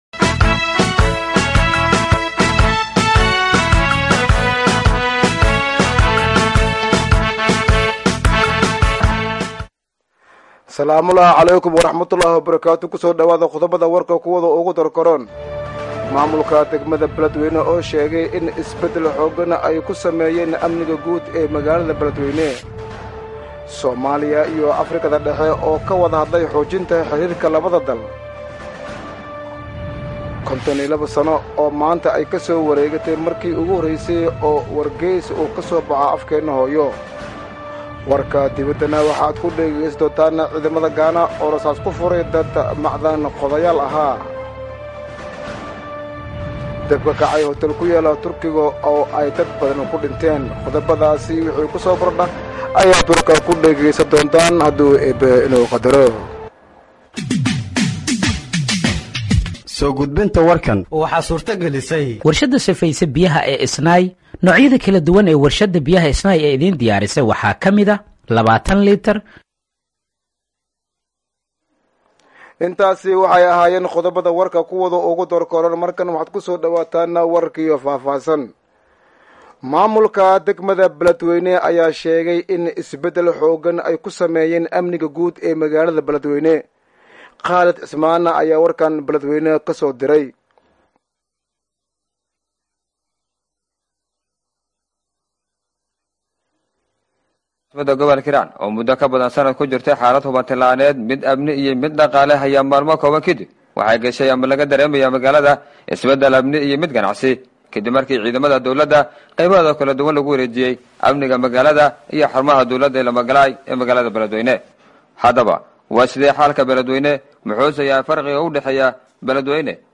Dhageeyso Warka Duhurnimo ee Radiojowhar 21/01/2025